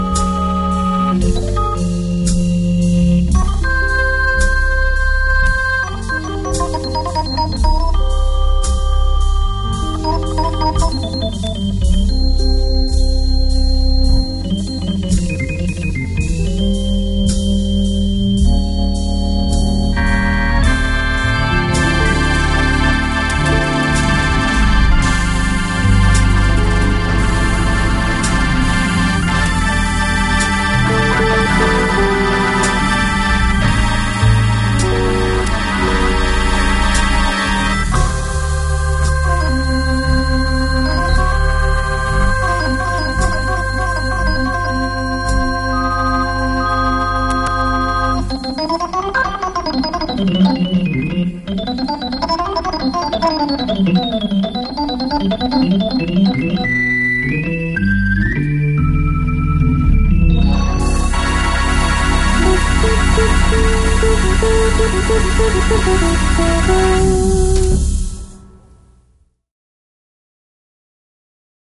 (par contre, les basses sont profondes, magnifiques).